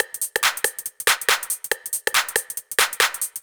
140 Ass Track Perc.wav